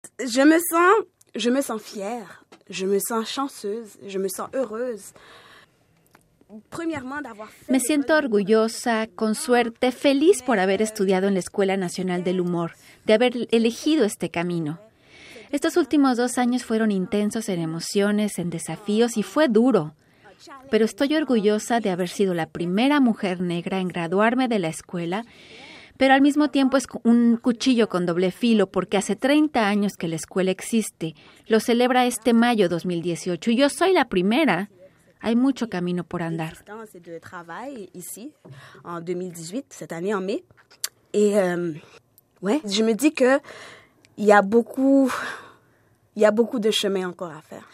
en entrevista con RCI